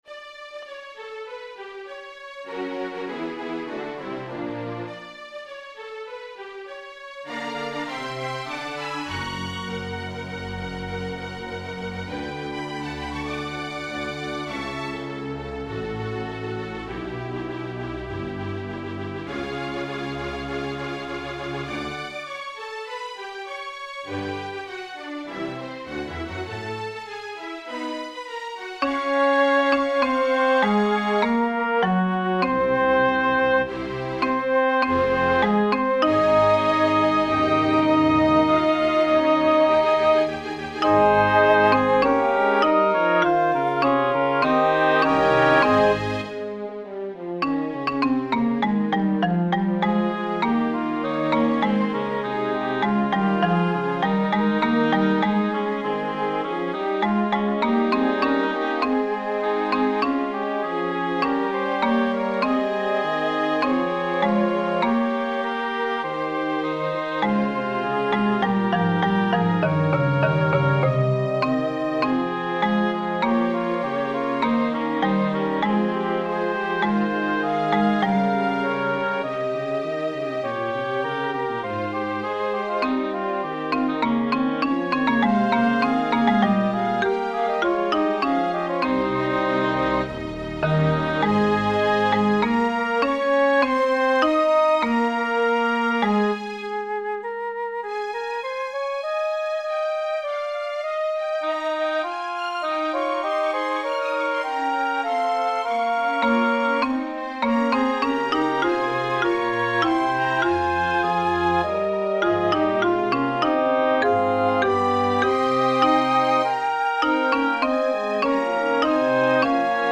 String Ensemble